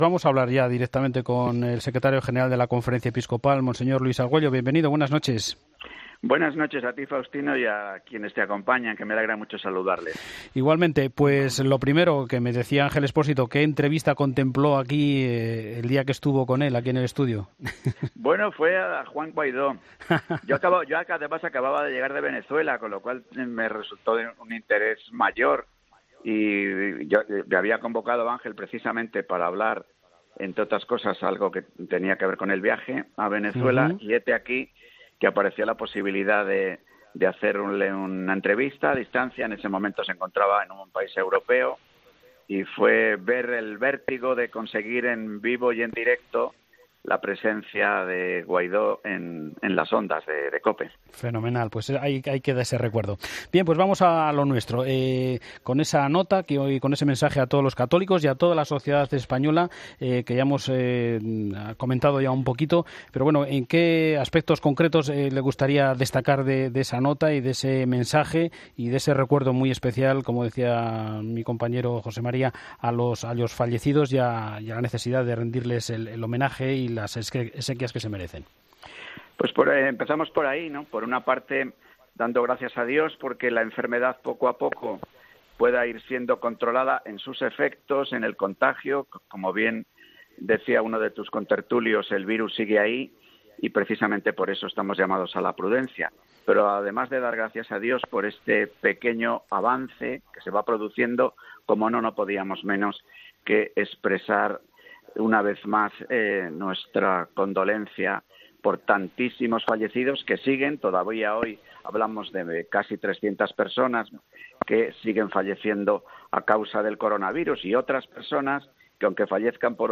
La presentadora de 'Fin de Semana' analiza la propuesta del partido de Iglesias de la 'Tasa Covid' a los ricos